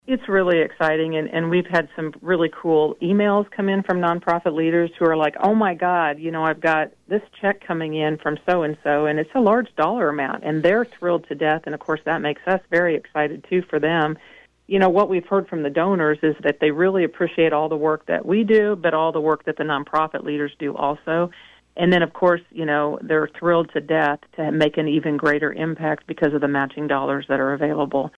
During an interview on KVOE’s Talk of Emporia Friday